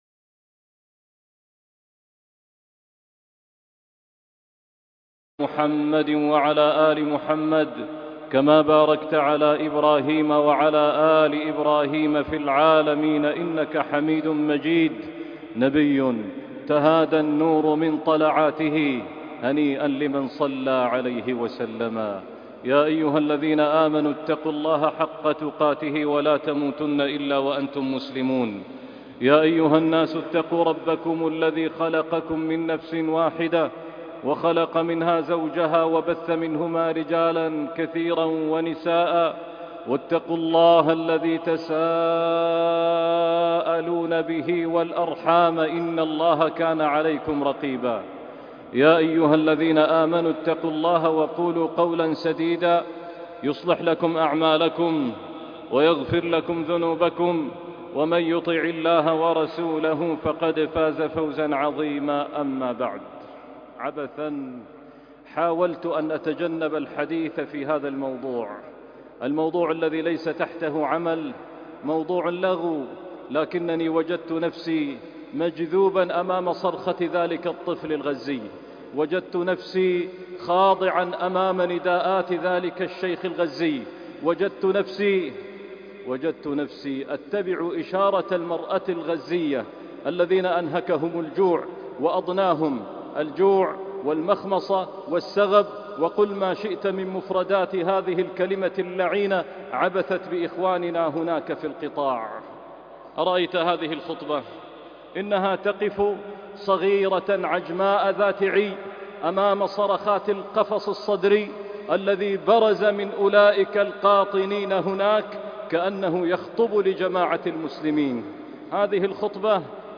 《القتل جوعا》خطبة وصلاة الجمعة